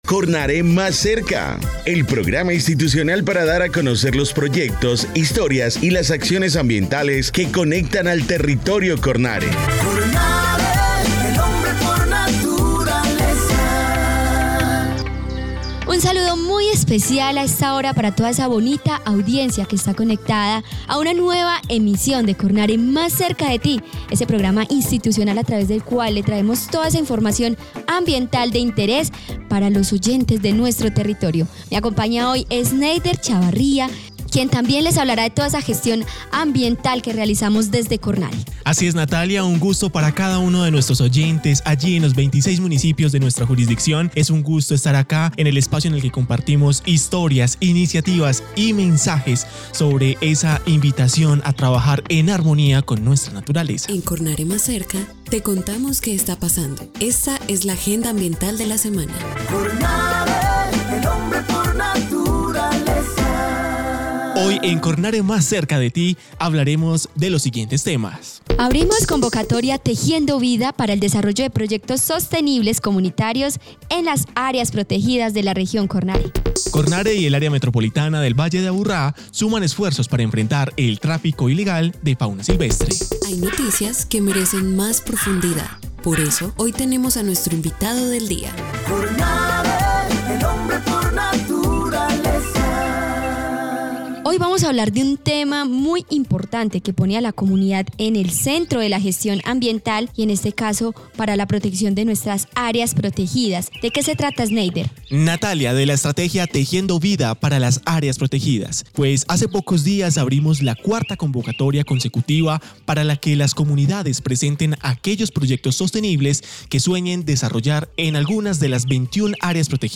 Programa de radio 2025